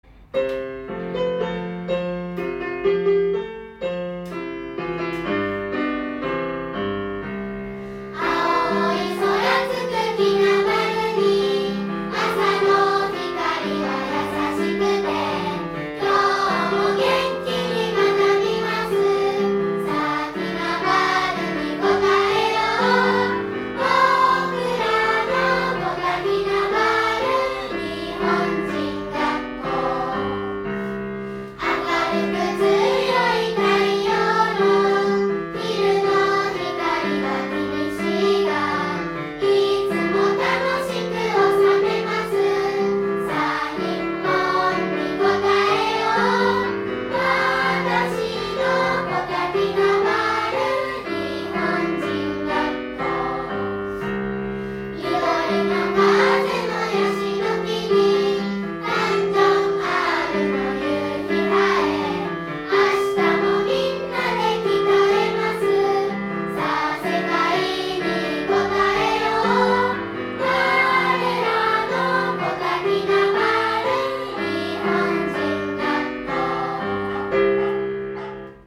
■元気を感じるリズムで、KJSの明るさが歌詞や旋律に表れていて好きです。（中1）